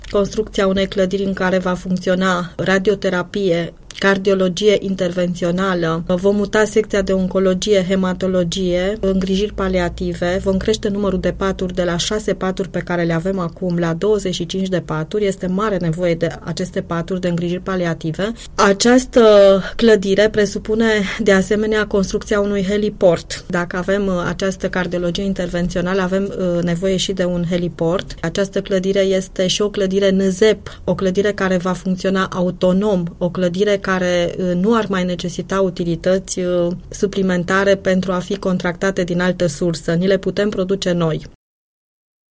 Prezentă în studioul Unirea FM